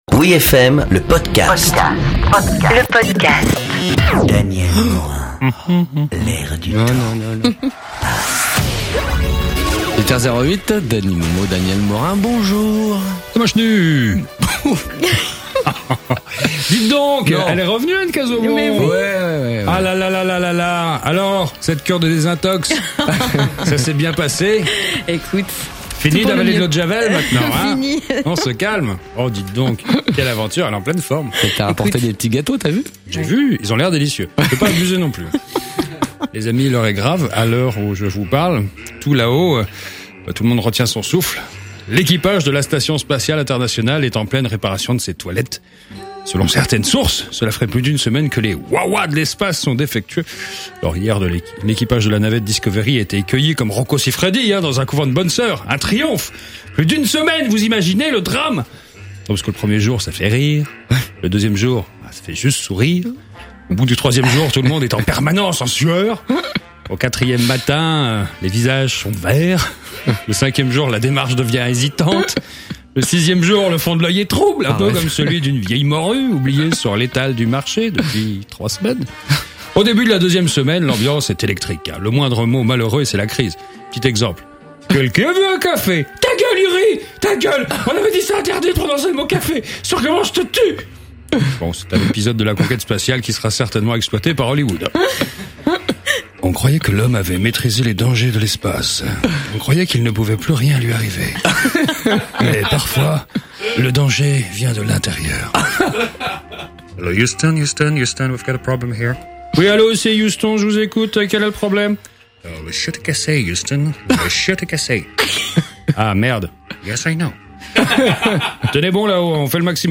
Cette chronique a été diffusée le 2 juin 2008 sur Oui FM, elle fait partie des « chroniques de l’air du temps selon Daniel Morin », vous pourrez en retrouver de nombreuses réunies sur ce site de chroniques radio.